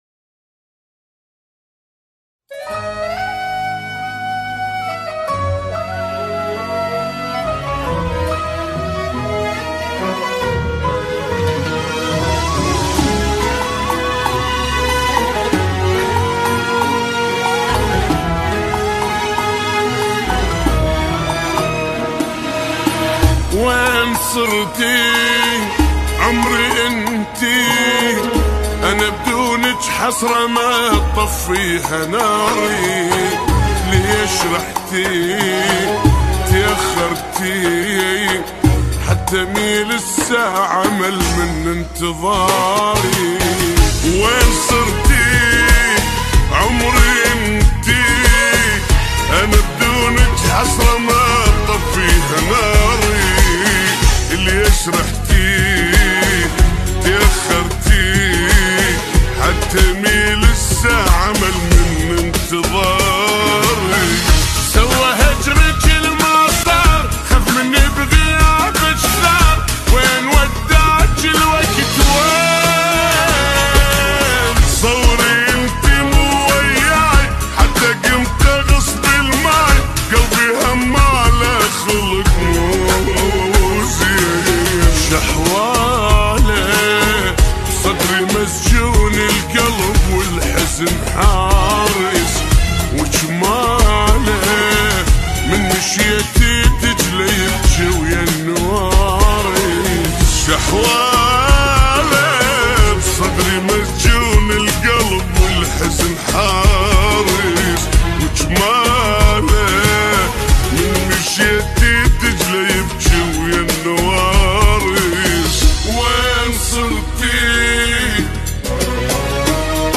عراقي جديد
بطيء